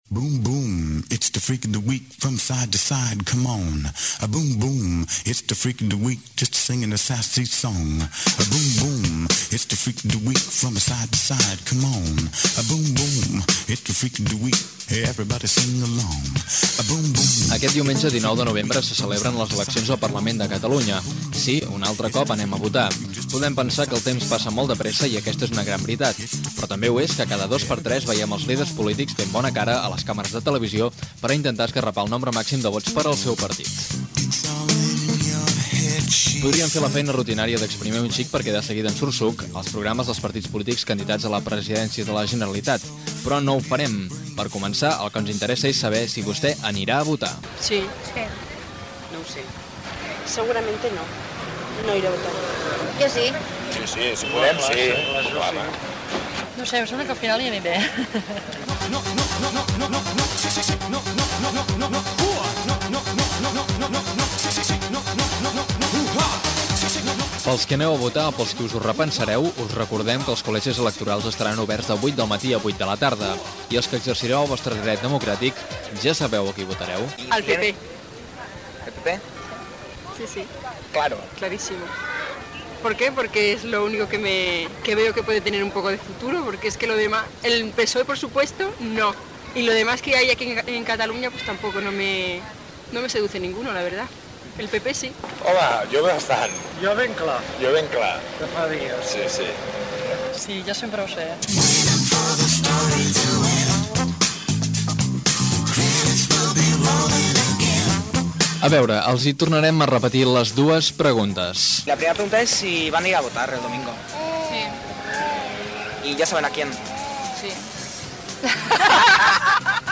Informatiu
Reportatge fet pels serveis informatius de Ràdio Rubí.